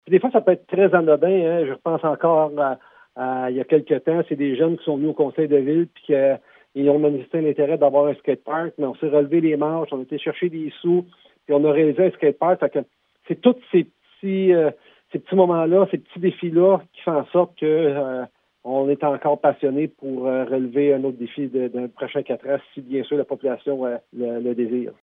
Monsieur Sarrazin soutient qu’il a encore une passion pour la politique municipale. Il raconte que cet amour pour son travail vient souvent de moments de contact avec les citoyens :